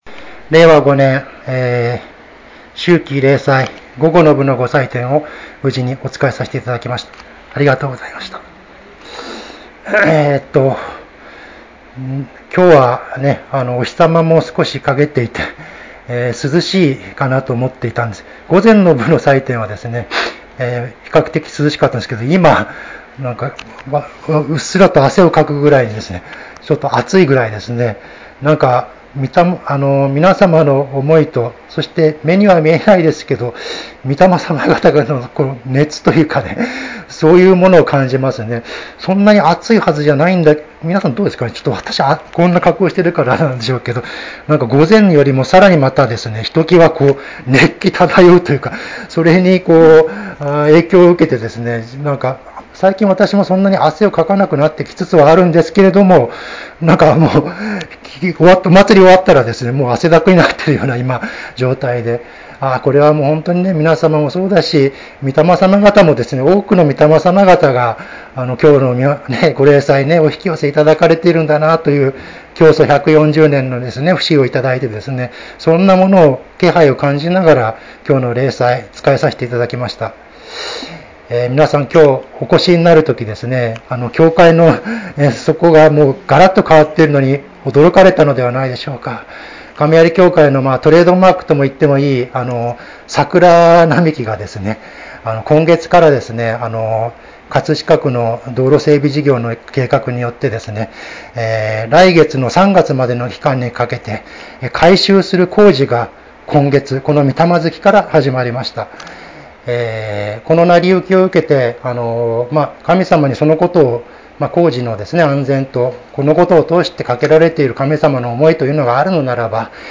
秋季霊祭教話